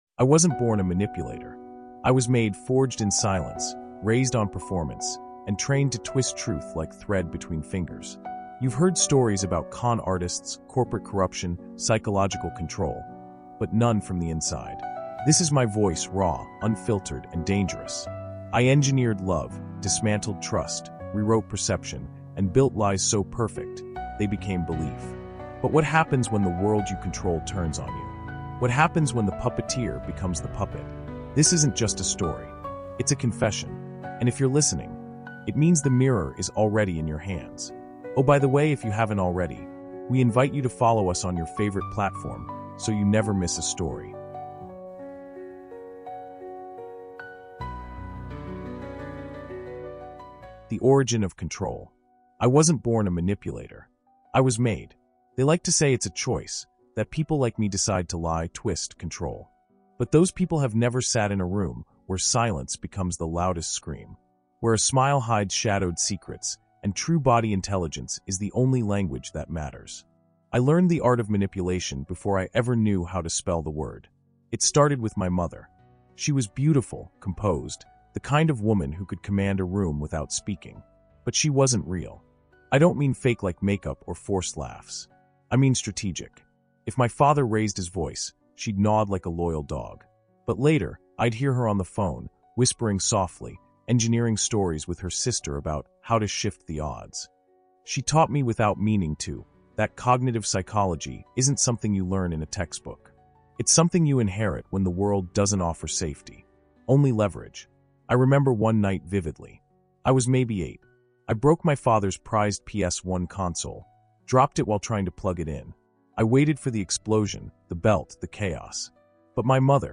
Step inside the mind of a master manipulator in this jaw-dropping, first-person psychological thriller.
This immersive story pulls back the curtain on the dark side of influence, manipulation, and emotional control. Told through the voice of a man who weaponized psychology, business strategy, and communication tactics, you'll witness how he manipulated relationships, exploited corporations, and orchestrated entire social movements.